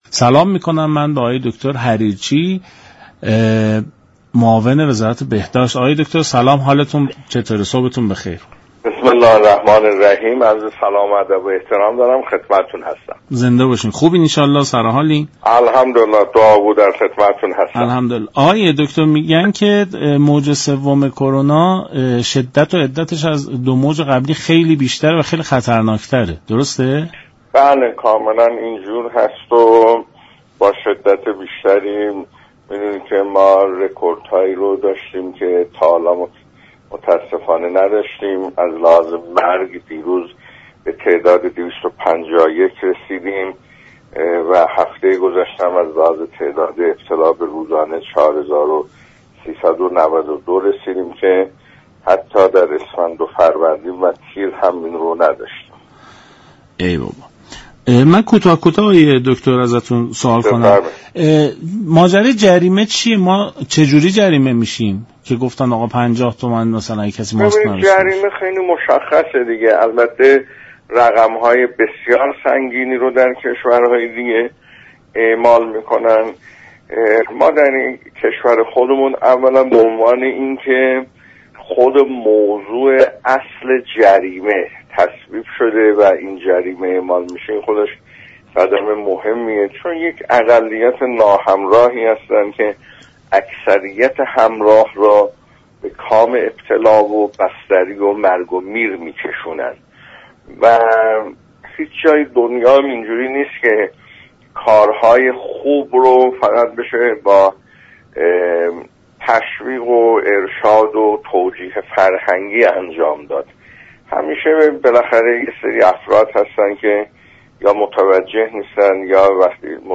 به گزارش شبكه رادیویی ایران، دكتر ایرج حریرچی معاون وزارت بهداشت در برنامه «سلام صبح بخیر» درباره موج سوم شیوع كرونا در كشور گفت: متاسفانه موج سوم ویروس كرونا این روزها با شدت بیشتری در حال شیوع است و حجم فوتی ها تا روز گذشته به عدد 251 نفر رسیده است.